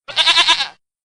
Ziege klingelton kostenlos
Kategorien: Tierstimmen
ziege.mp3